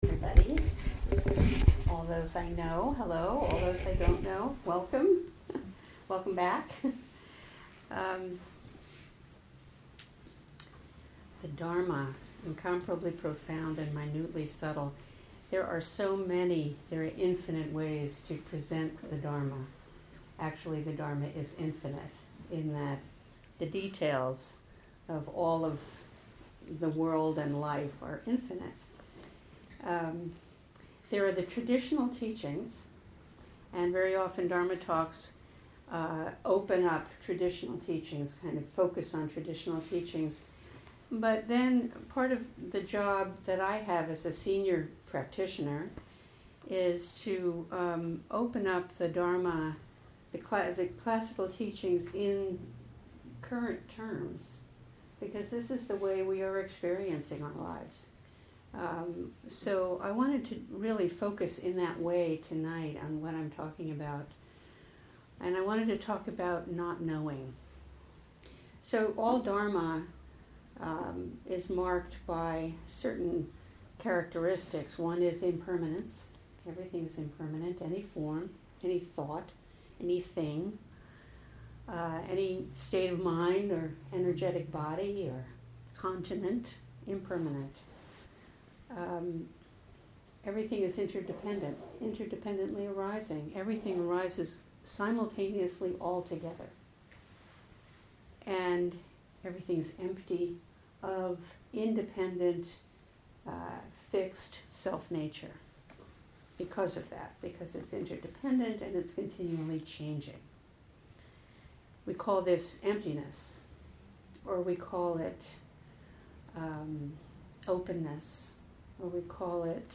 Dharma talks are prepared talks that focus on specific Buddhist topics.